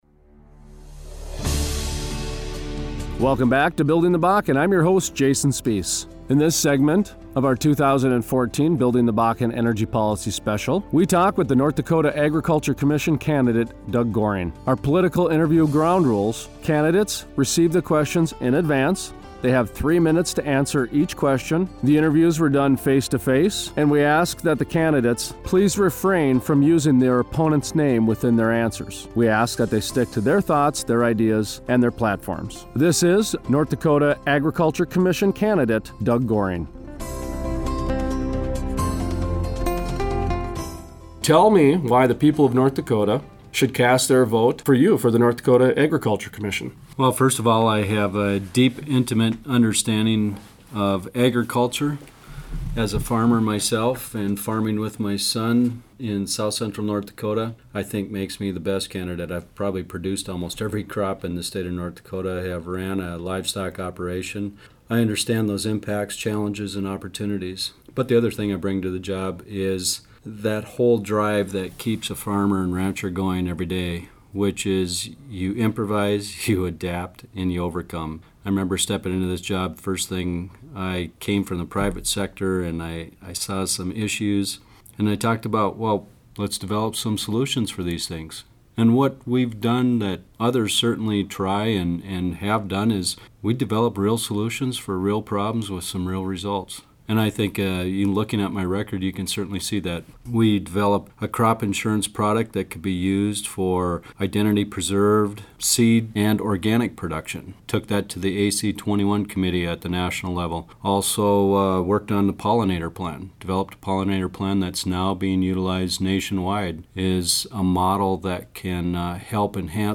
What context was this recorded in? For our Energy Policy Special, candidates were provided three questions in advanced, limited to three minutes per question and were asked to refrain from using their candidate’s name in their response. All interviews were conducted in person.